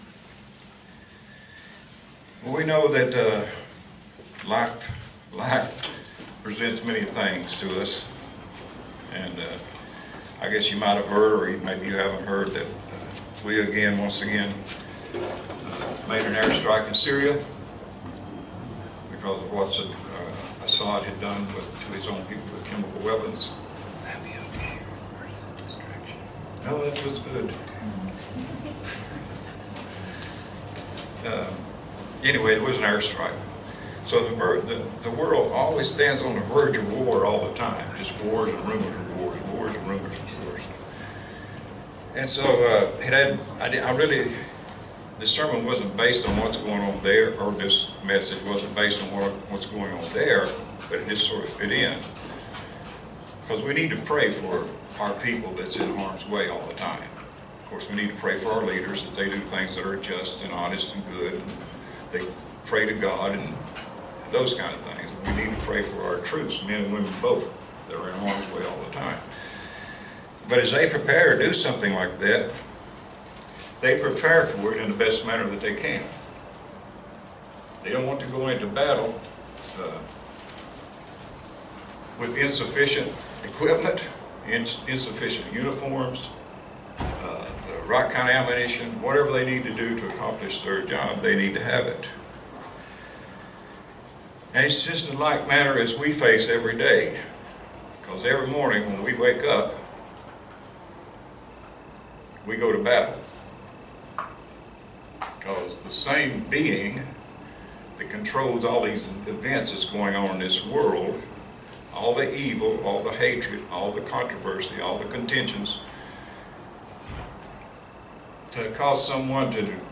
Given in Roanoke, VA